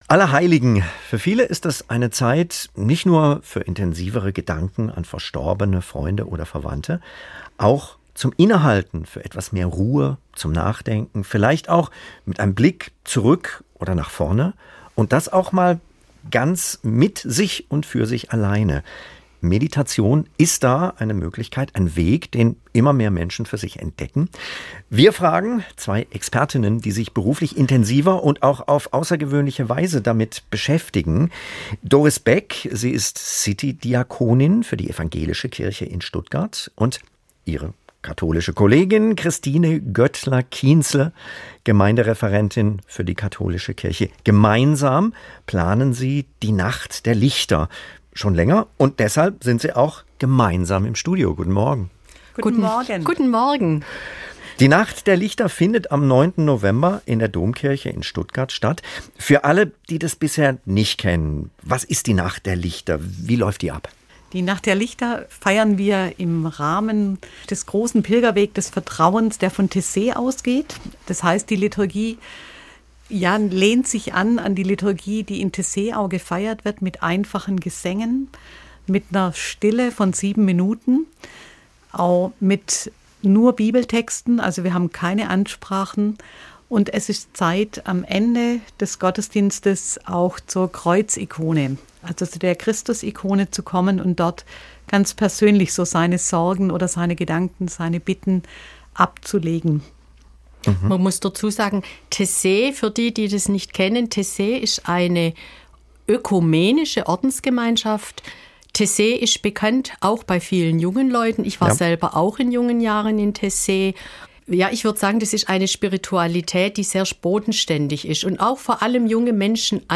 Beide erzählen im Gespräch mit "SWR1 Sonntagmorgen", was Ihnen in Sachen Stille, Ruhe, Transzendenz finden wichtig ist.